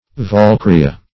Valkyria \Val*kyr"i*a\, n. [Icel. valkyrja (akin to AS.